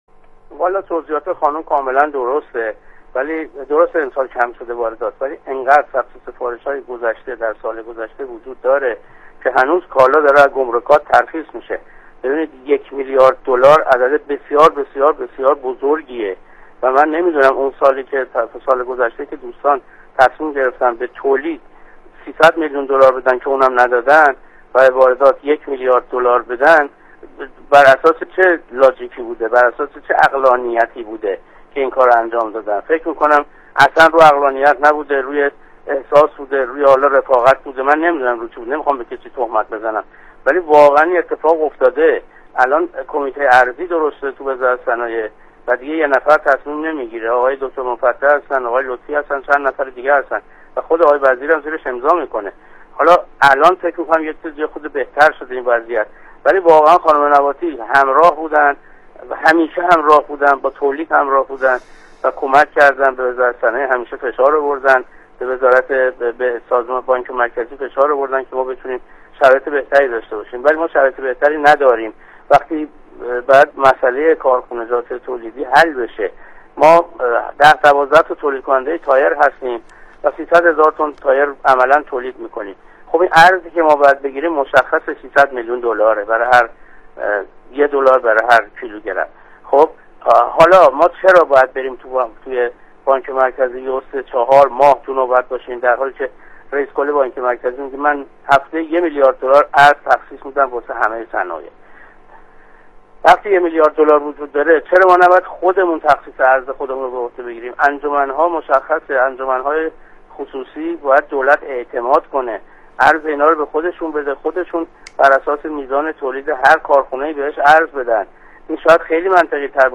فایل صوتی گفتگوی